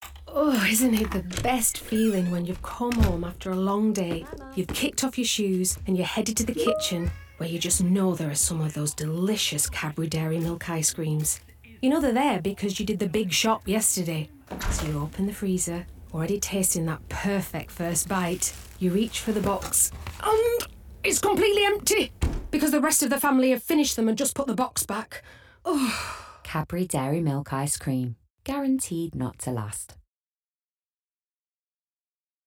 Lancashire
Northern
Female
Bright
Conversational
Warm
CADBURY COMMERCIAL